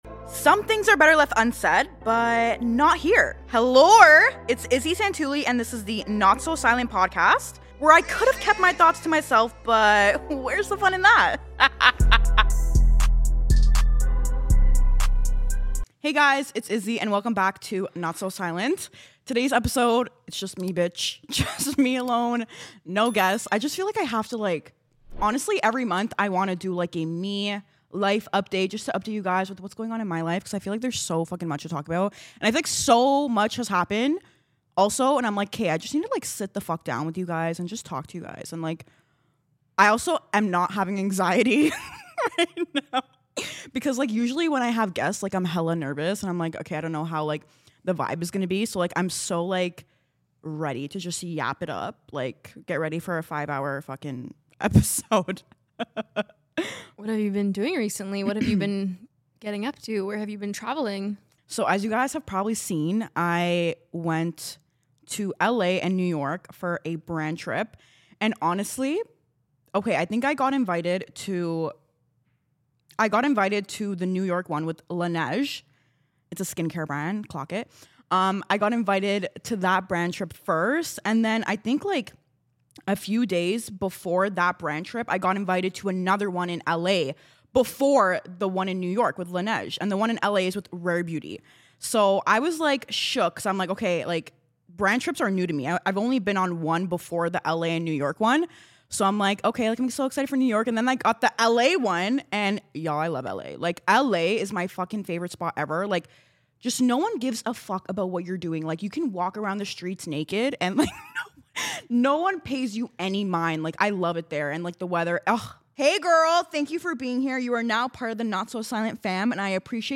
Expect unfiltered convos, zero awkward silences, and probably a few questionable takes.